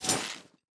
Equip H.wav